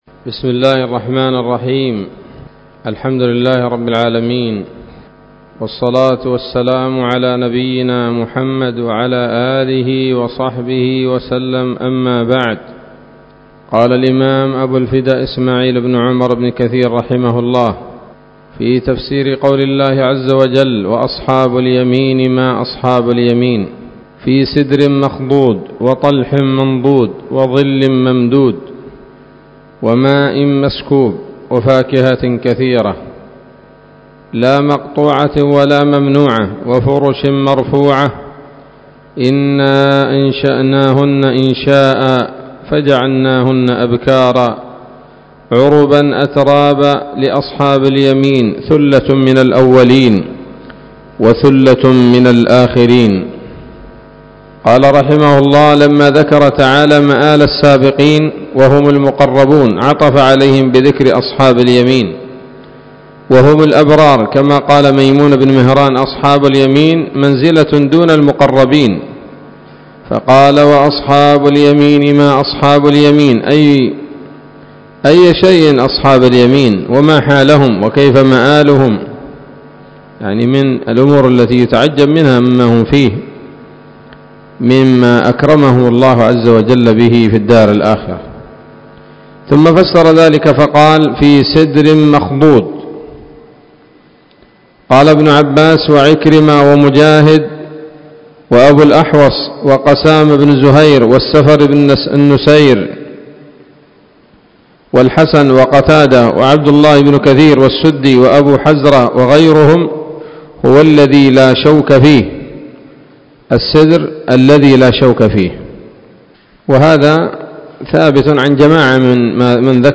الدرس السادس من سورة الواقعة من تفسير ابن كثير رحمه الله تعالى